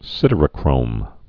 (sĭdər-ə-krōm)